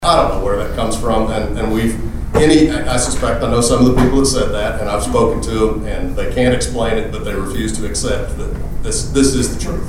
In response to an audience question about a false claim that voting yes on the propositions equates to a tax increase, Bailey says he has met and answered several questions from some vocal people who oppose the propositions.